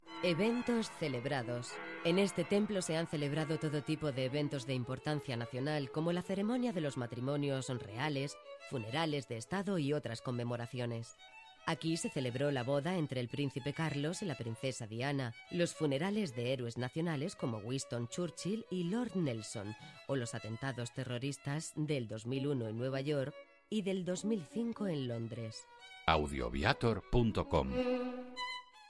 audioguía_Catedral_de_Saint_Paul_Londres_14.mp3